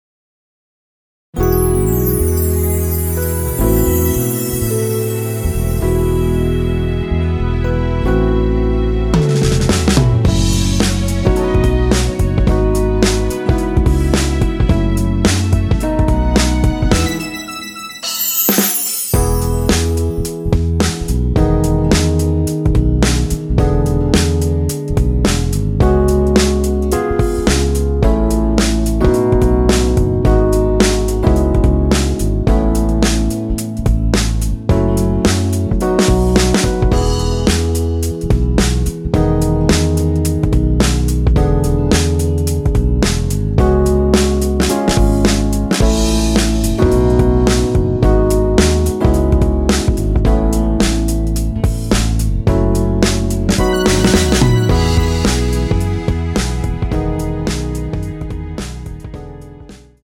원키에서(-1)내린 MR입니다.
앞부분30초, 뒷부분30초씩 편집해서 올려 드리고 있습니다.
중간에 음이 끈어지고 다시 나오는 이유는